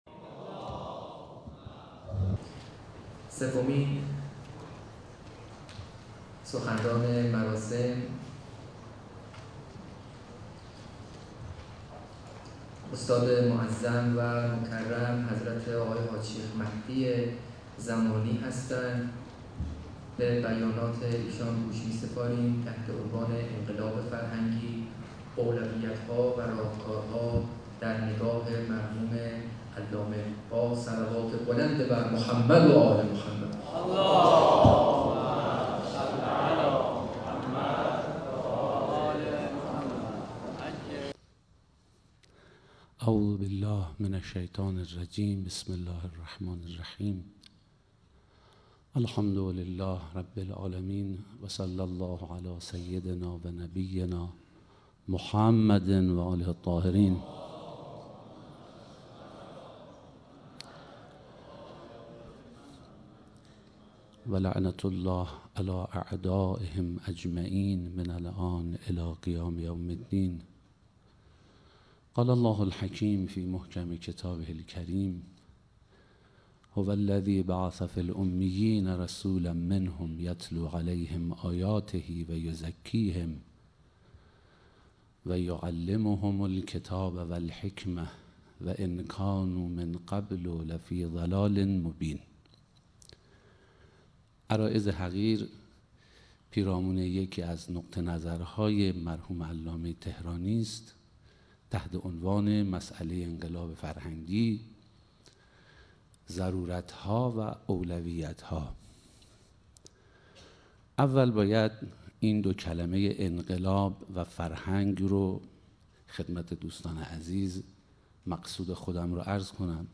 سخنرانی
در همایش نور مجرد